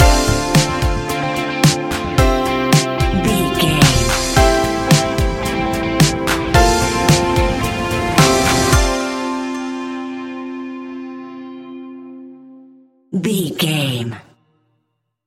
Ionian/Major
ambient
electronic
new age
downtempo
pads
drone